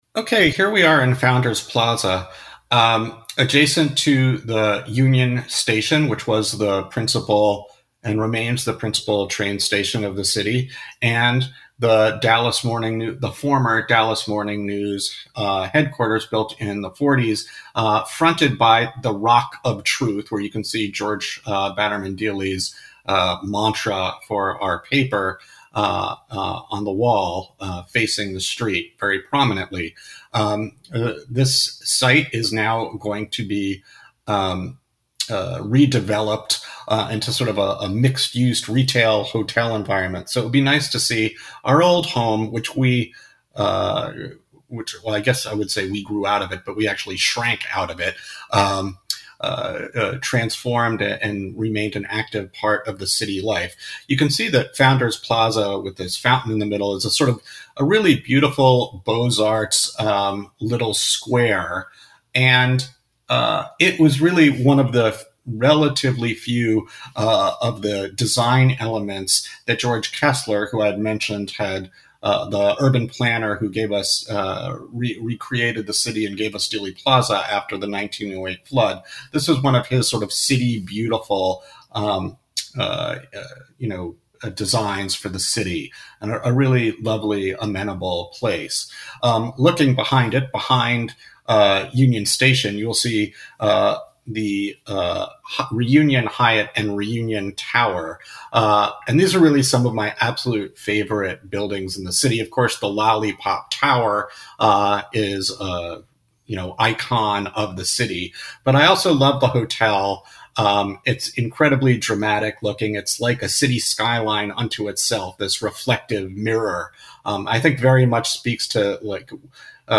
7 - Explore Dallas audio tour: Founders Plaza, Union Station and Reunion Tower